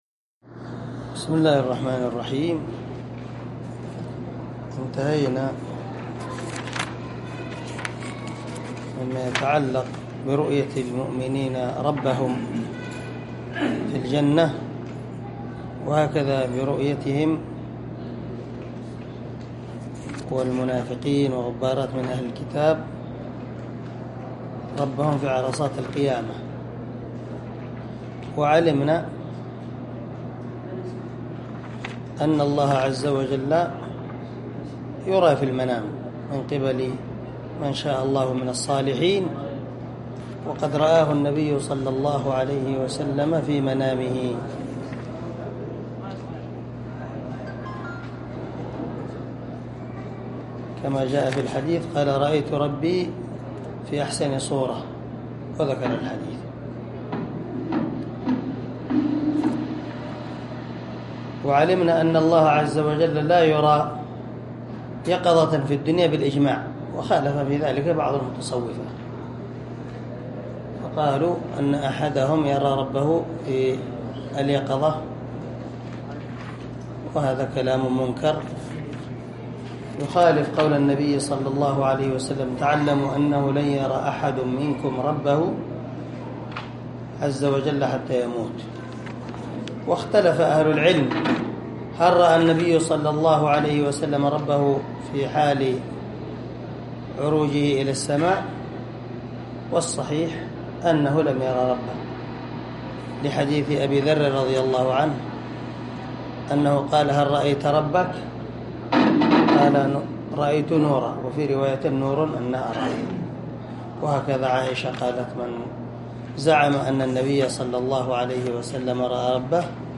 شرح العقيدة الواسطية للعلامة محمد بن خليل هراس رحمه الله – الدرس الرابع والأربعون
دار الحديث- المَحاوِلة- الصبيحة.